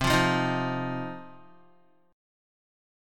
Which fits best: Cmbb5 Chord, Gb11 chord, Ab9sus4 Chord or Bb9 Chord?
Cmbb5 Chord